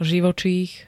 Zvukové nahrávky niektorých slov
ei25-zivocich.ogg